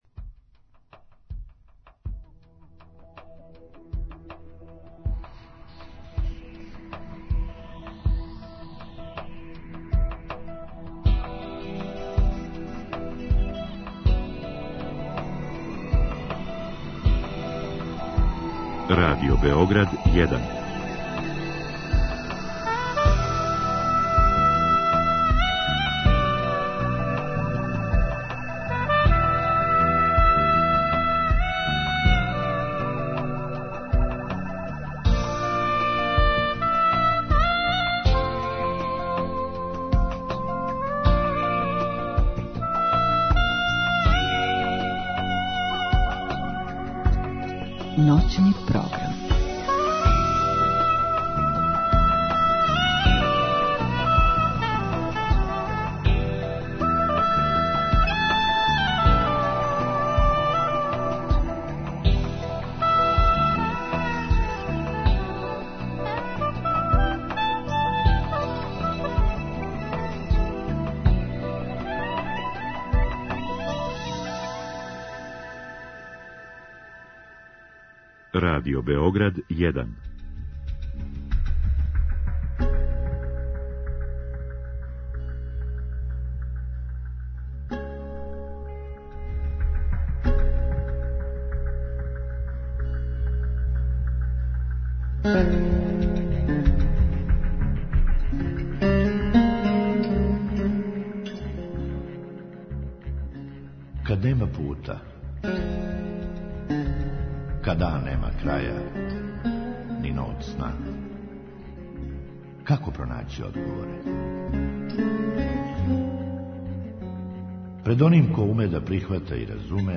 Ноћашња емисија биће читава два сата отворена за дијалог са слушаоцима. Поставићемо једно питање на које свако може да одговори и лично и 'безлично', али нам пружа могућност да заједно осмислимо како би наши односи са другима могли да се побољшају и да се заинтереованост, брига за друге и заједништво поново “доселе” у наше крајеве.